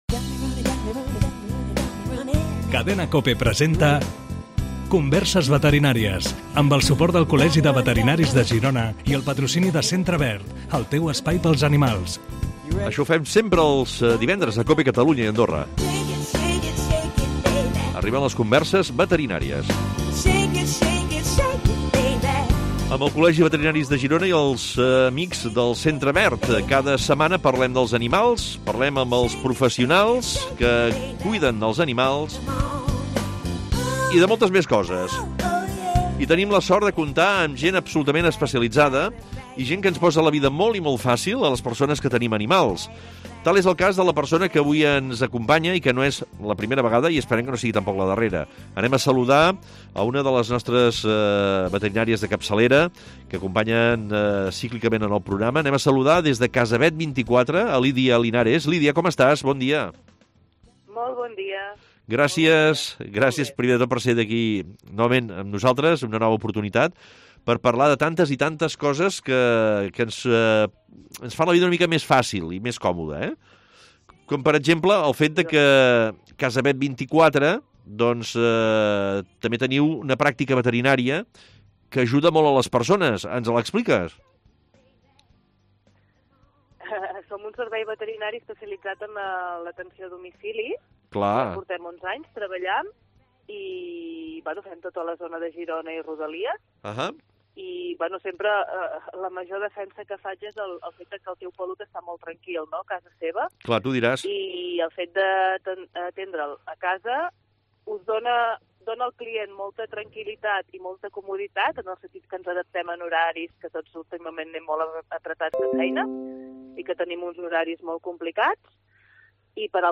Es contesta per ràdio a les preguntes de propietaris de gossos i gats.
Tot el que envolta el món dels animals ho pots escoltar tots els divendres després de les notícies de les 13 hores a Cope Catalunya i Andorra.